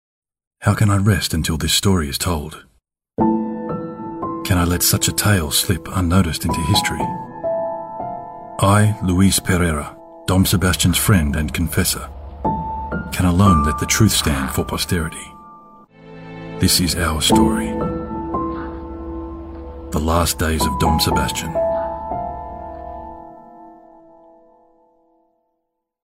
Narration
Rode NT-1, Sennheiser 416 microphones